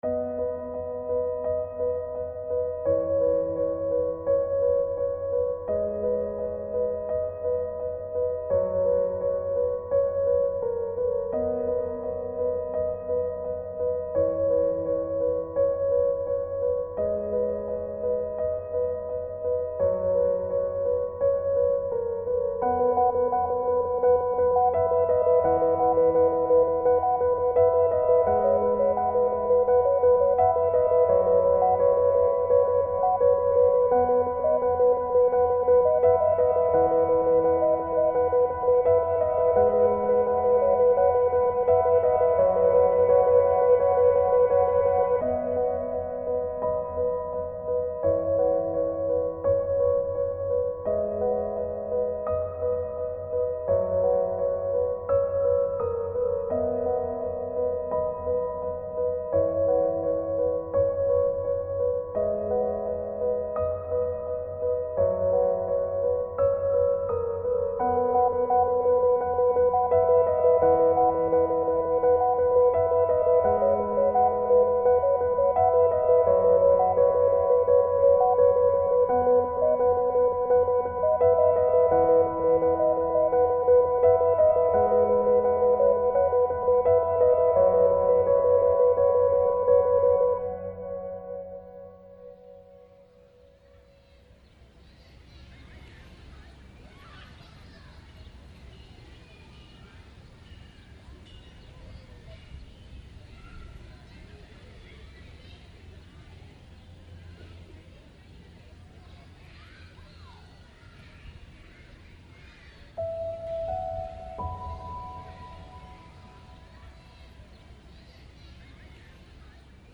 instrumental pieces